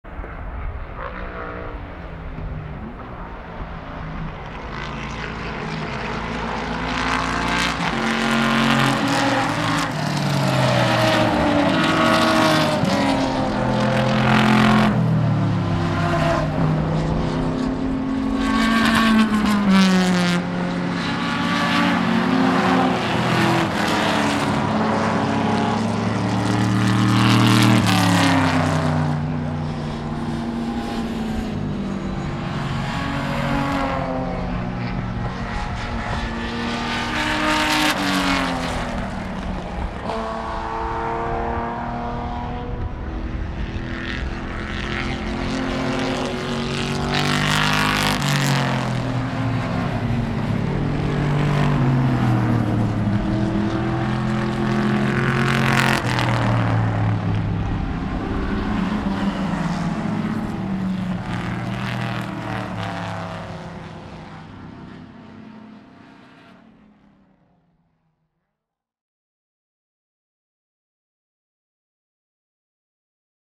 Zu diesem Anlass wird die Strecke in der Sarthe während 45 Minuten für historische Rennfahrzeuge geöffnet, ein Moment, der viele Le Mans Enthusiasten erfreut.
Motoren Symphonie von Le Mans Legend 2011 - Erste Runde